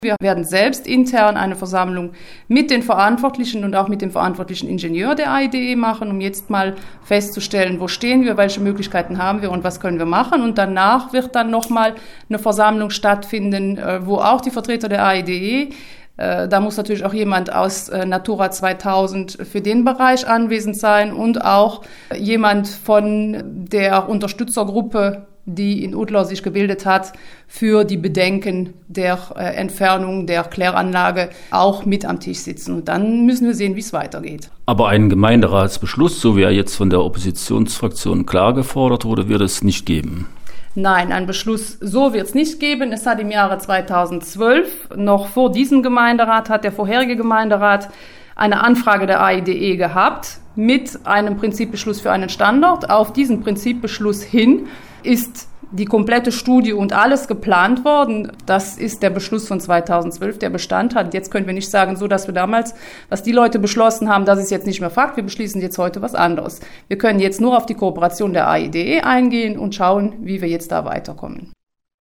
unterhielt sich